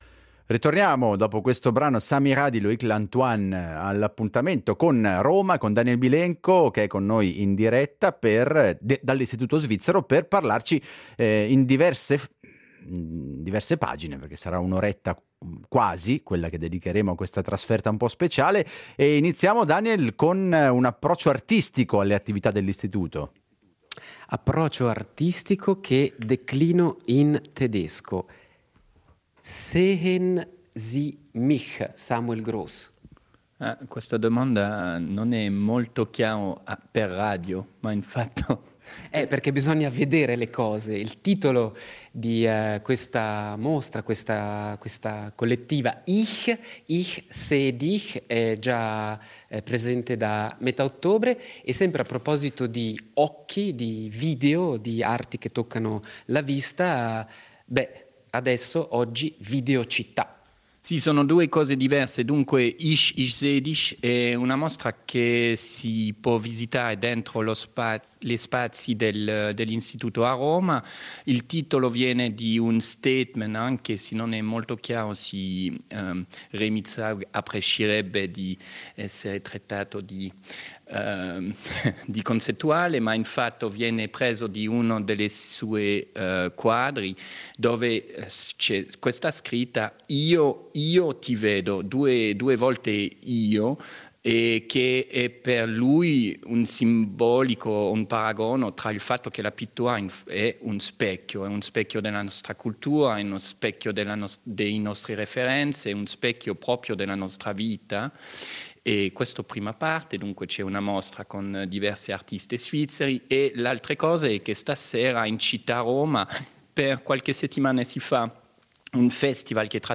in diretta da Roma